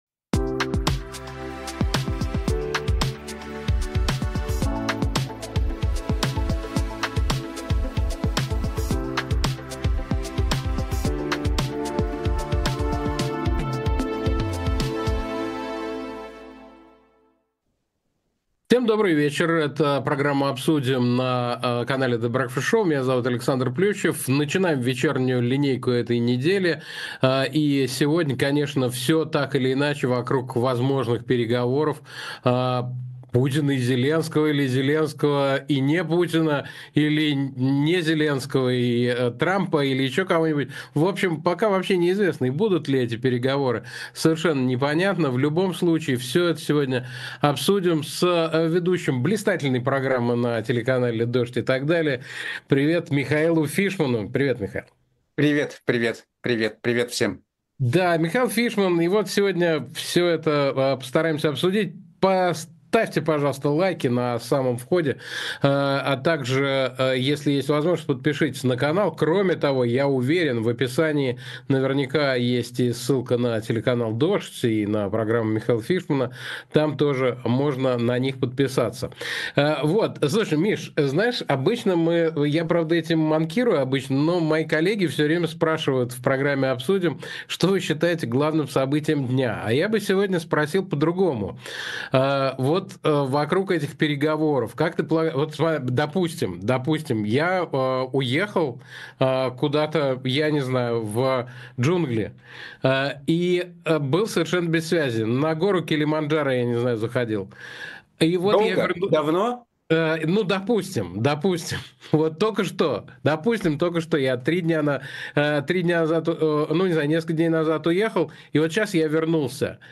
Эфир ведёт Александр Плющев
Гость сегодняшнего выпуска — журналист телеканала «Дождь» Михаил Фишман. Обсудим с ним, состоится ли встреча в Стамбуле, в каком составе, если да, а также успел ли пожалеть о своем предложении.